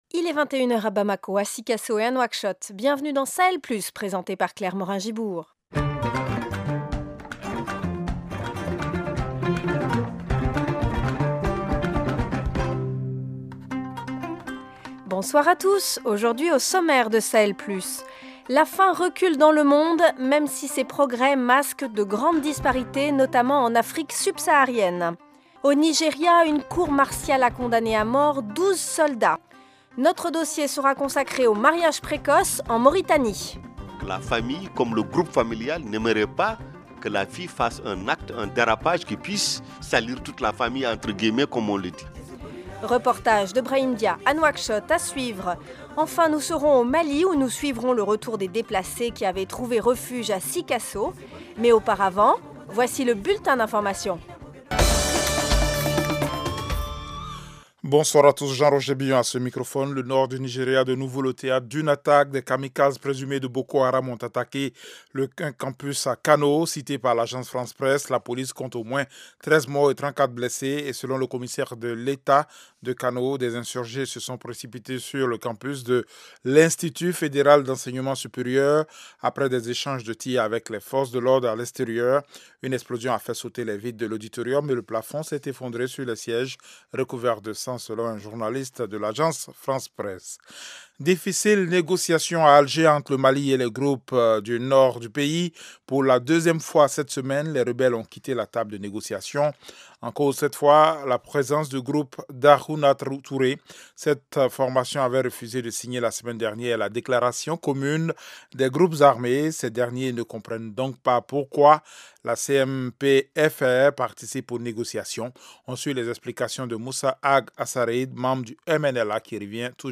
Au programme : la faim recule dans le monde, des progrès mais de grandes disparités en Afrique Subsaharienne. Au Nigéria, une cour martiale condamne à mort 12 soldats. Reportage